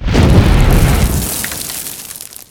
coverblow.wav